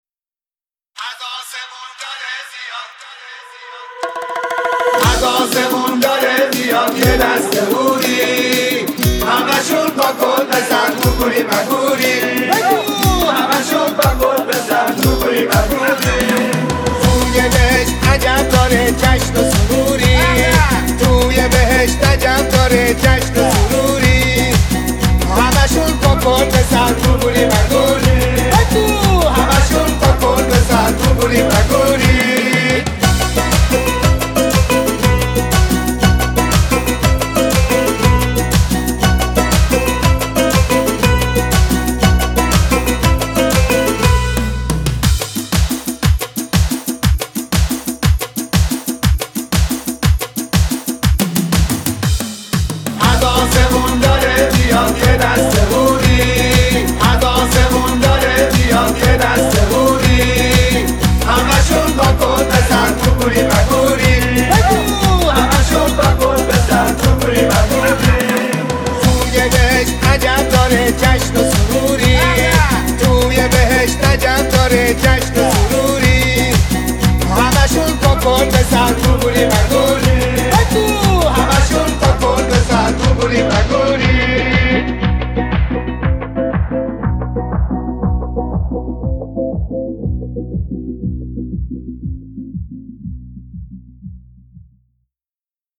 Download Old Remix BY